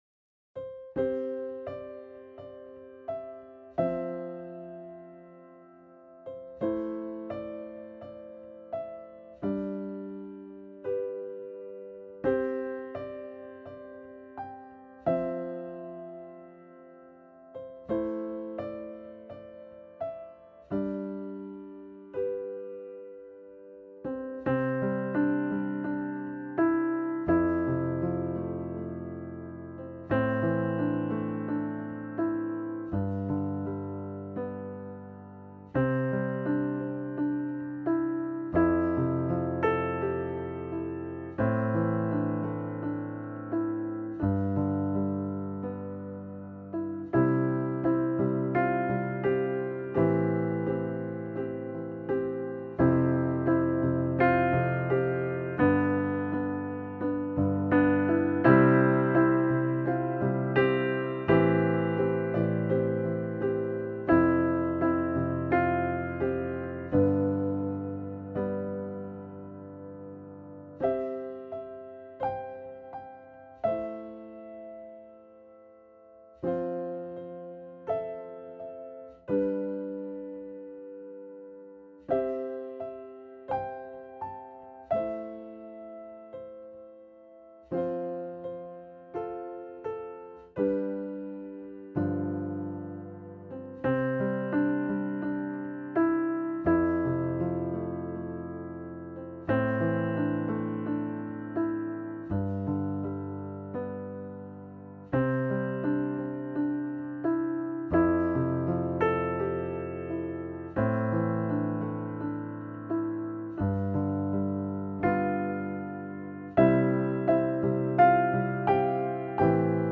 Успокаивающая музыка для отдыха на пианино